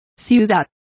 Complete with pronunciation of the translation of metropolis.